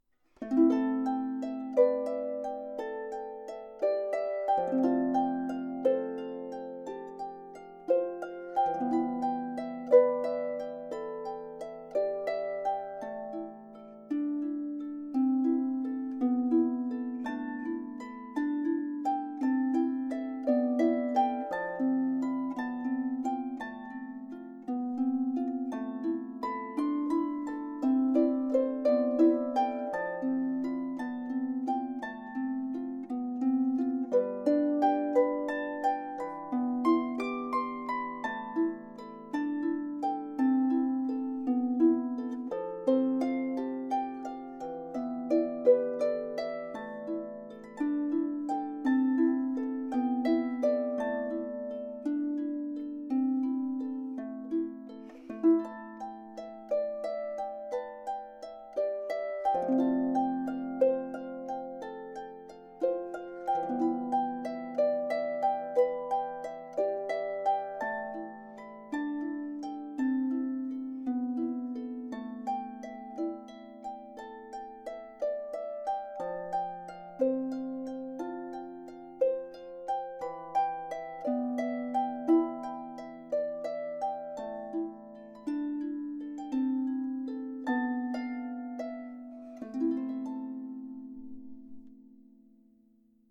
Harfe "Ivy"
klein & klangstark
Ivy - Freie Improvisation 1.mp3